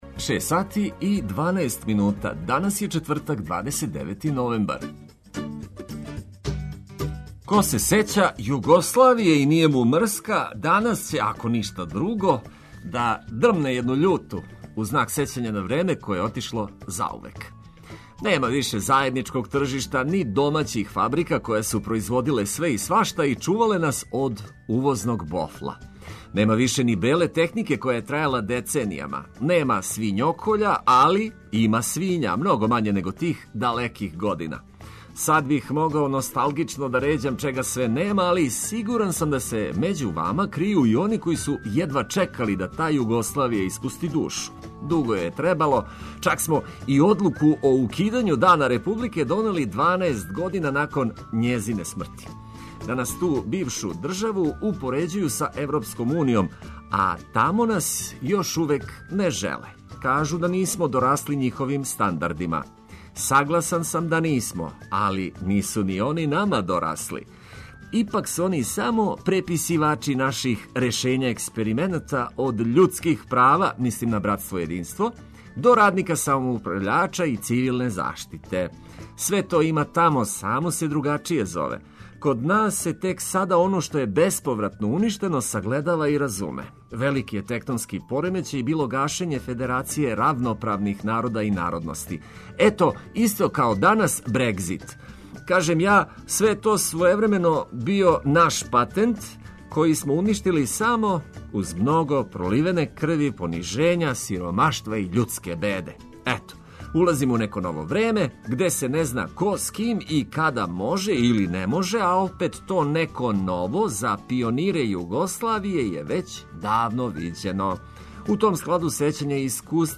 Ми смо припремили све, понајвише добру музику за буђење.